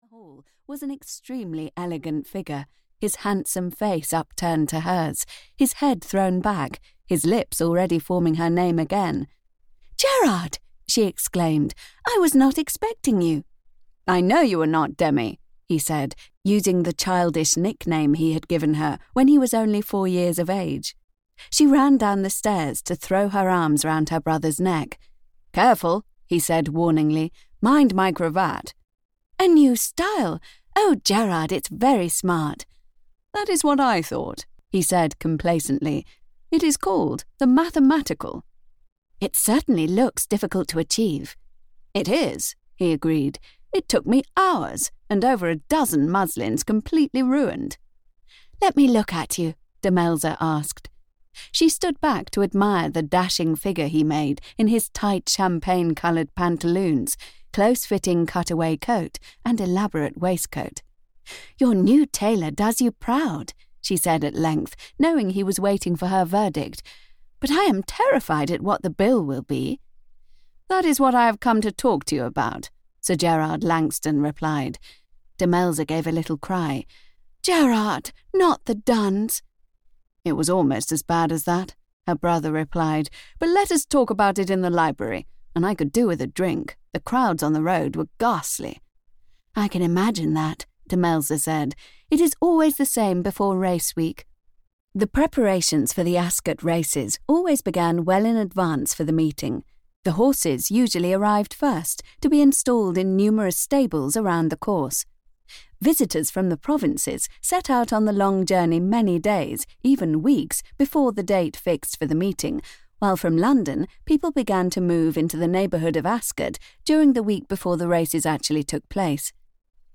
The Ghost Who Fell in Love (EN) audiokniha
Audiobook The Ghost Who Fell in Love, written by Barbara Cartland.
Ukázka z knihy